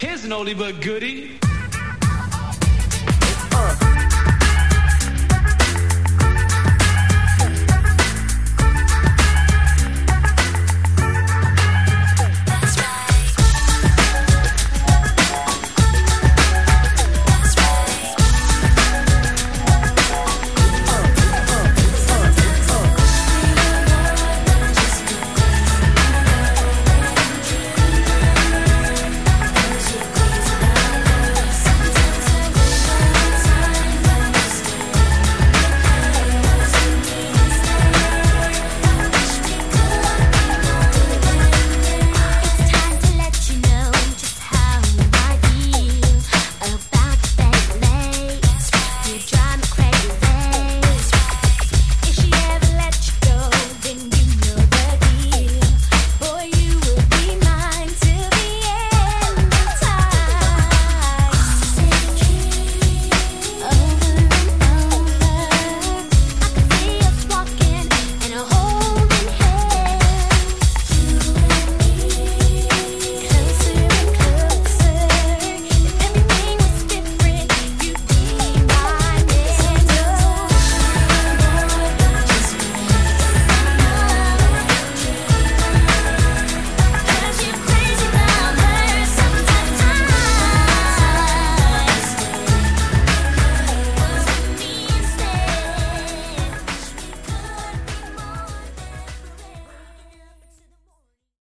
SPECIAL REMIX ＆ オリジナル・バージョン レア 12 スペリミ 試聴